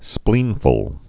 (splēnfəl)